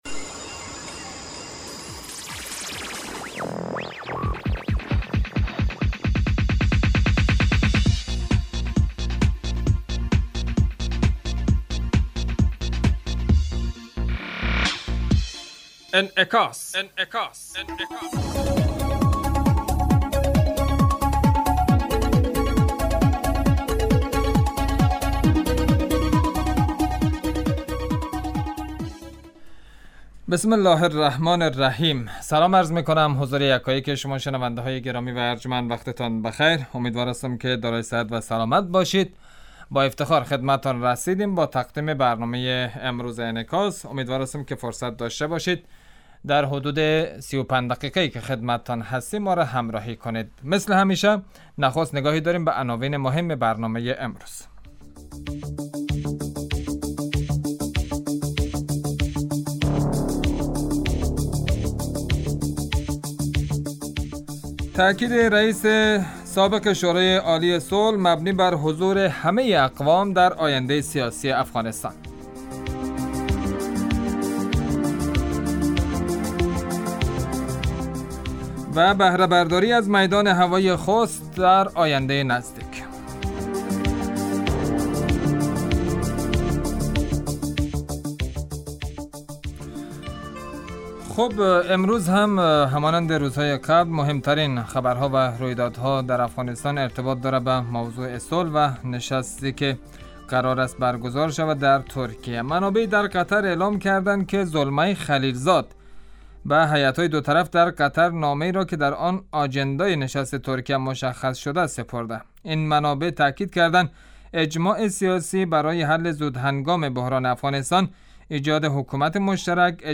برنامه انعکاس به مدت 35 دقیقه هر روز در ساعت 12: 15 ظهر (به وقت افغانستان) بصورت زنده پخش می شود.